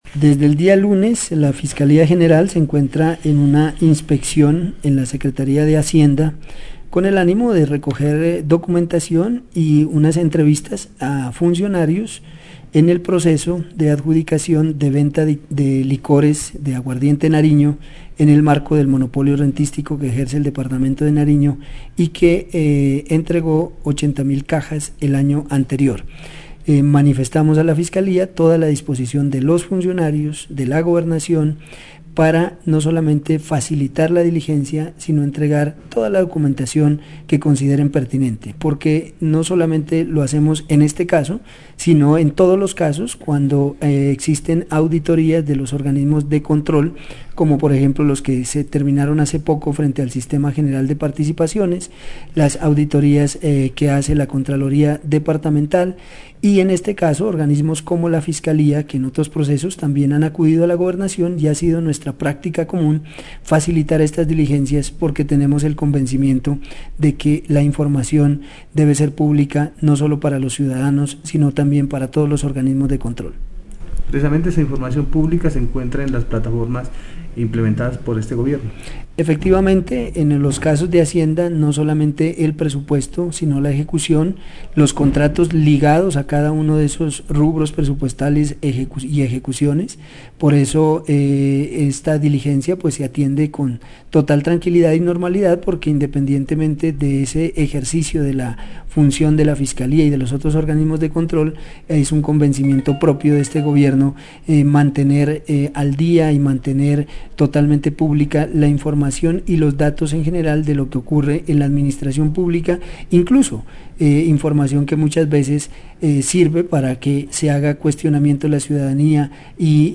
MARIO_BENAVIDES-_SECRETARIO_DE_HACIENDA_DEL_DEPARTAMENTO.mp3